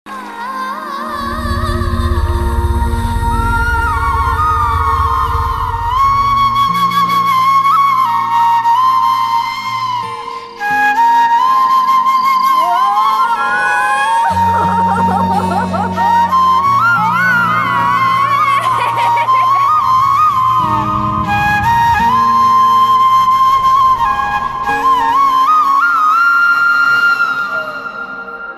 Category: Sad Ringtones